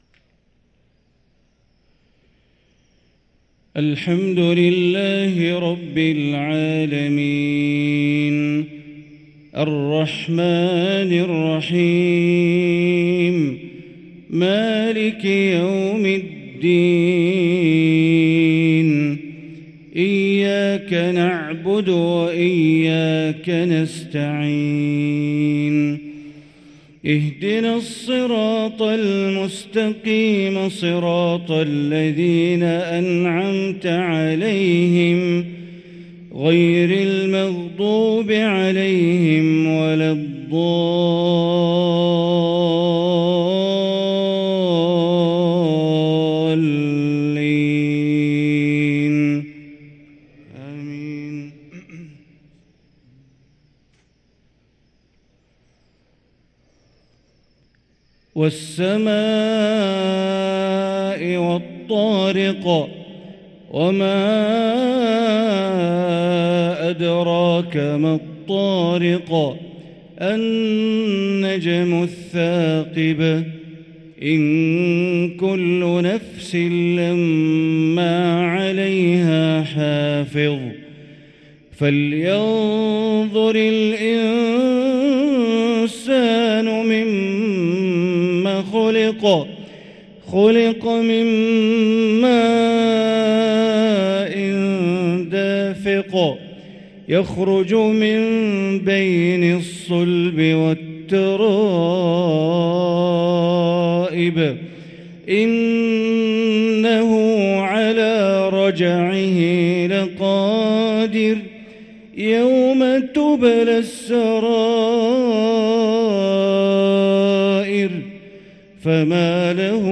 صلاة المغرب للقارئ بندر بليلة 5 شوال 1444 هـ